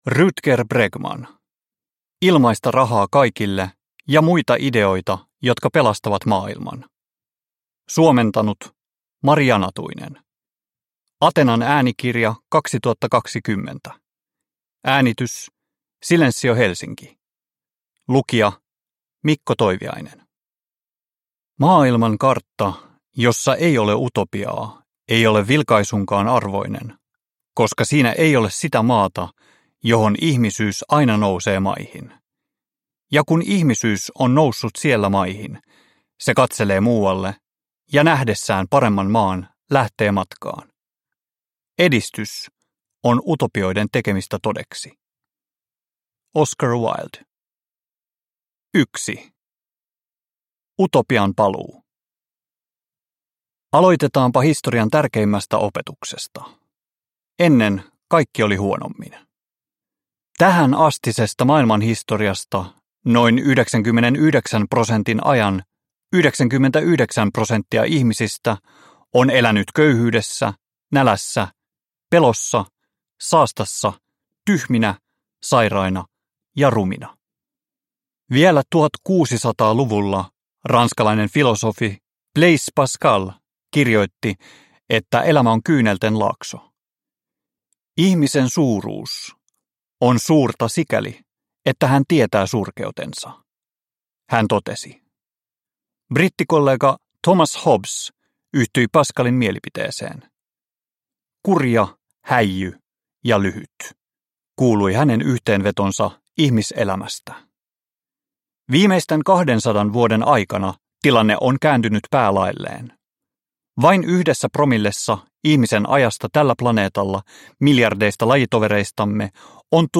Ilmaista rahaa kaikille ja muita ideoita, jotka pelastavat maailman – Ljudbok – Laddas ner